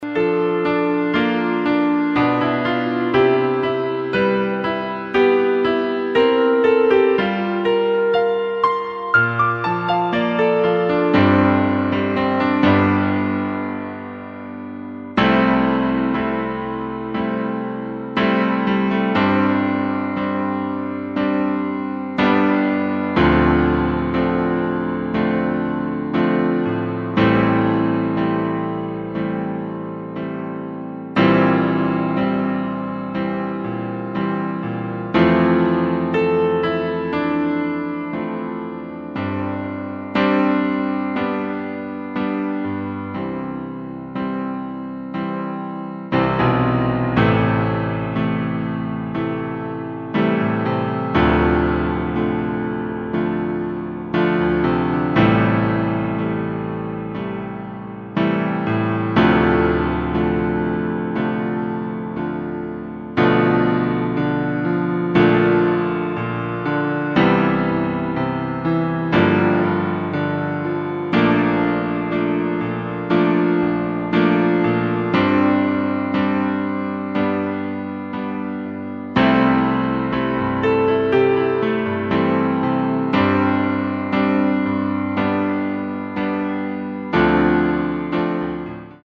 Klavier / Streicher